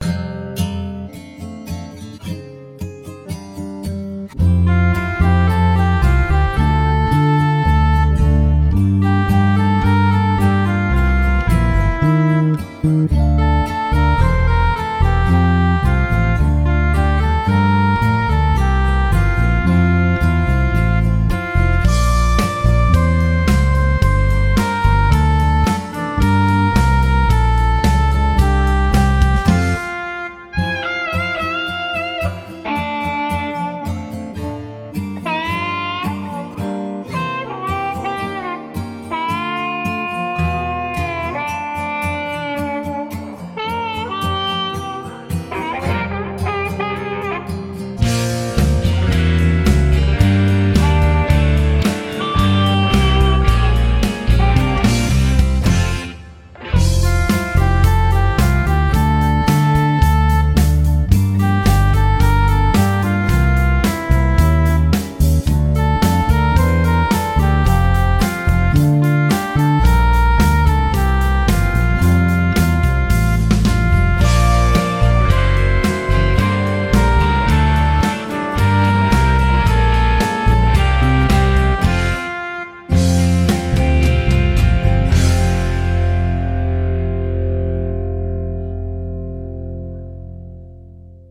Deux versions instrumentales